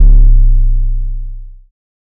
808 (Down).wav